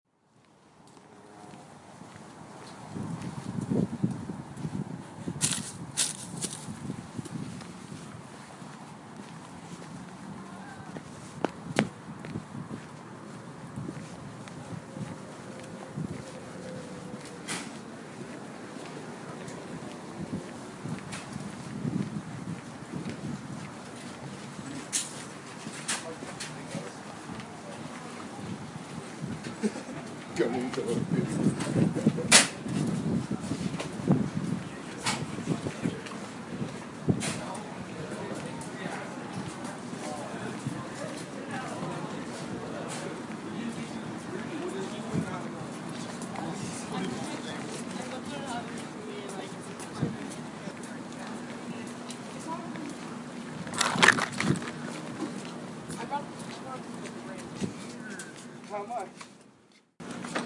描述：Loyola高中一分钟的音乐会。这个声音有人群的噪音，还有树叶嘎吱作响，还有一个储物柜砰地关上
Tag: 现场记录 更衣室 交谈